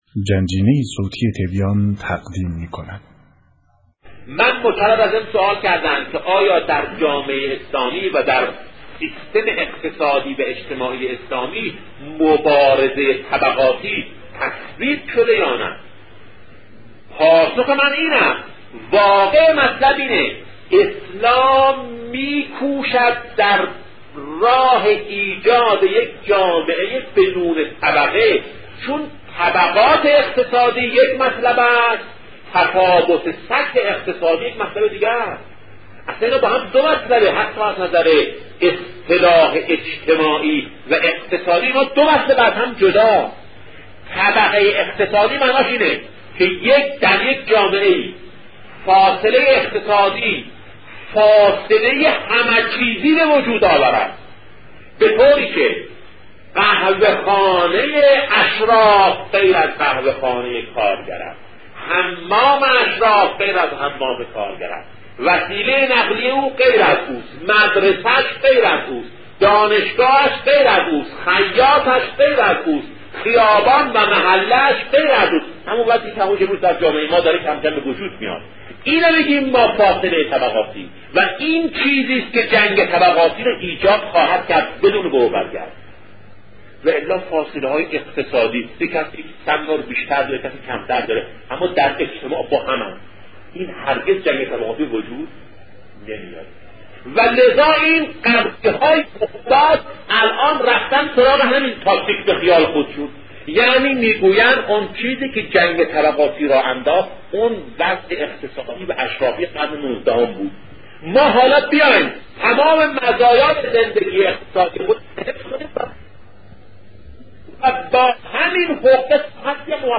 صوت شهید بهشتی(ره)، اسلام می‌کوشد در راه ساختن جامعه ی بدون طبقه
صوت بیانات بزرگان